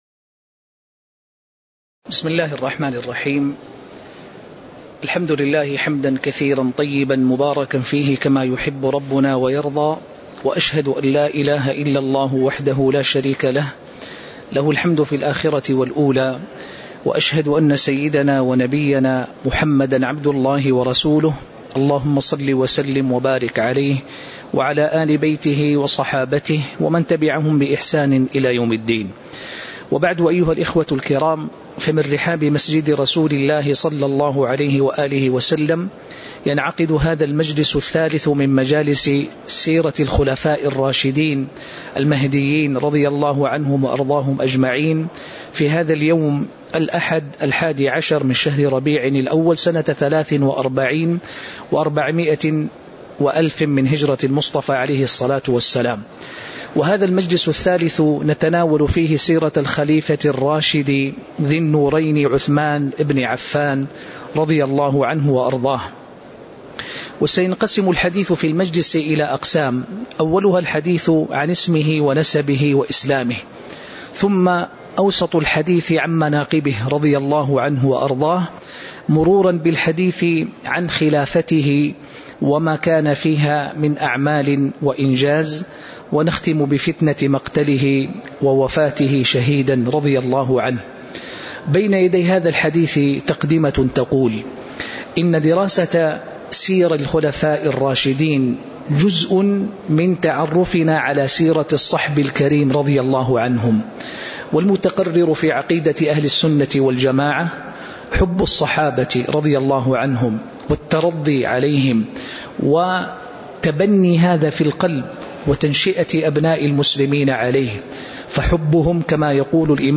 تاريخ النشر ١١ ربيع الأول ١٤٤٣ هـ المكان: المسجد النبوي الشيخ